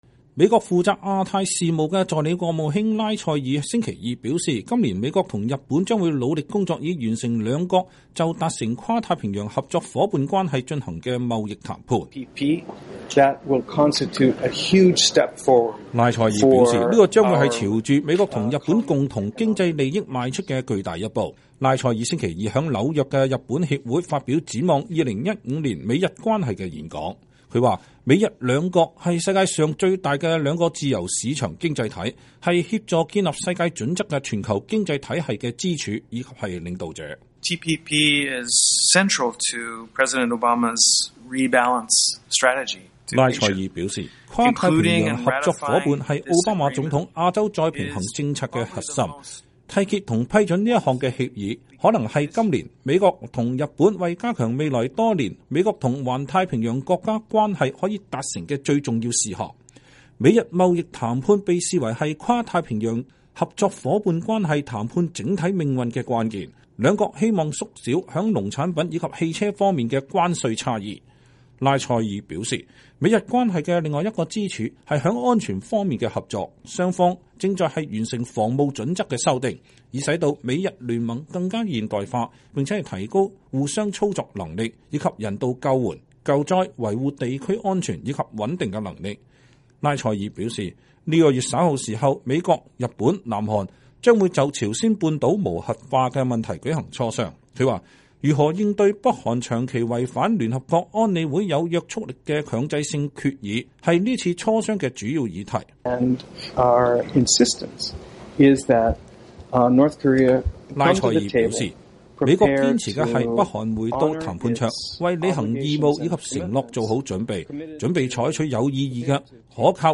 拉塞爾星期二在紐約日本協會發表展望2015年美日關係的演講。